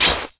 assets/ctr/nzportable/nzp/sounds/weapons/ballknife/shoot.wav at a21c260aab705f53aee9e935cc0f51c8cc086ef7